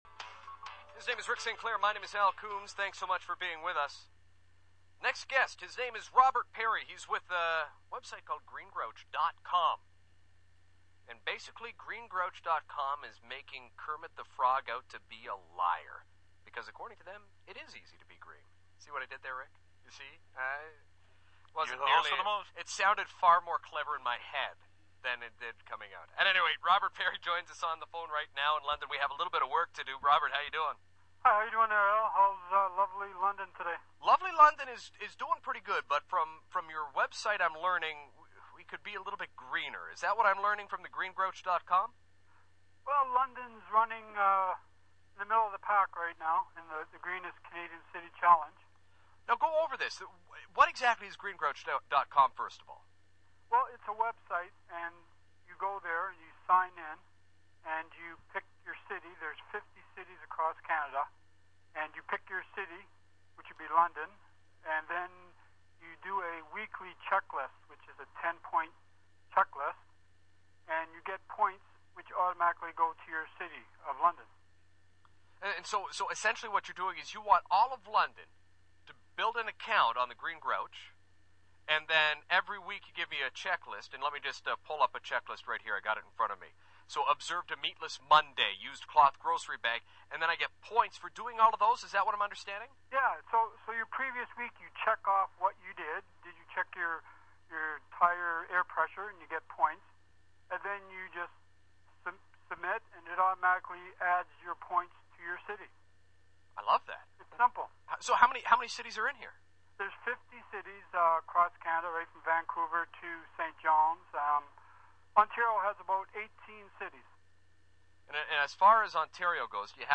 CJBK FM 1290 Radio Interview (London) April 19, 2011